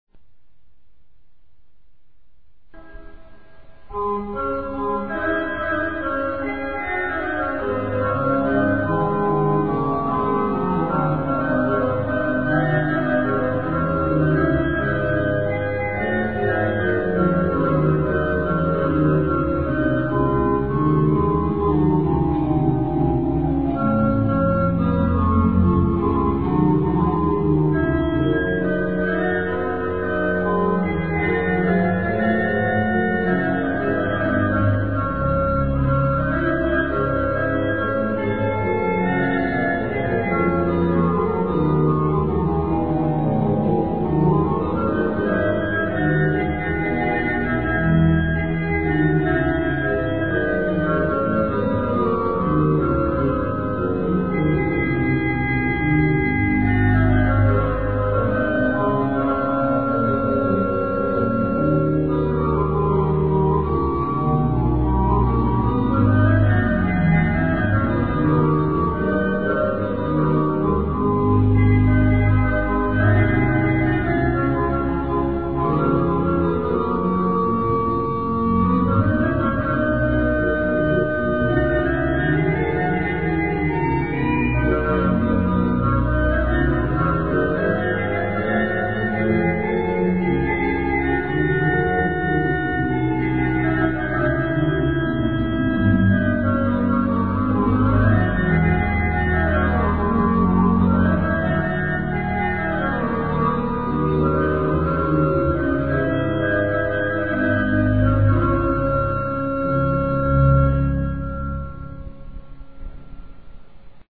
FILES AUDIO DAL VIVO
(organo della chiesa di Saint Martin a Dieppe - Normandia - Francia)
organo